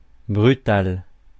Ääntäminen
IPA : /ˈvɪʃəs/